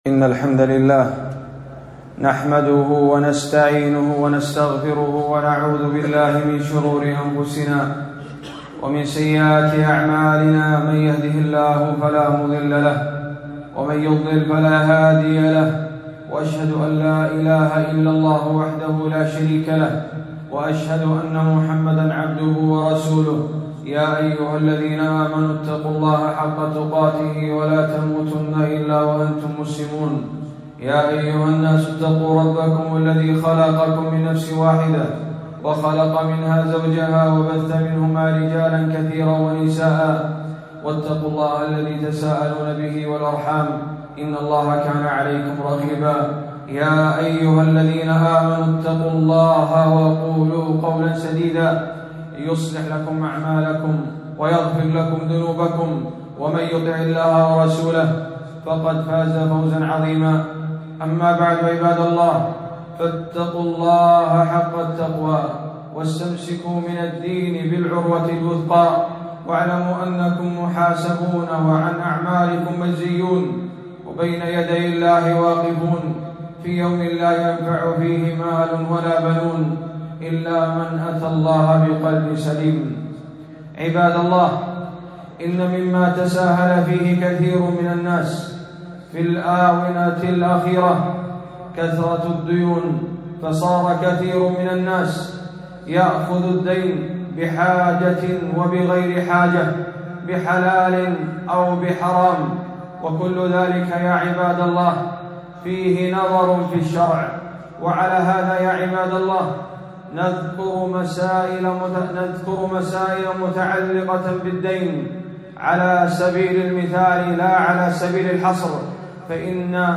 خطبة - من أحكام الدين - دروس الكويت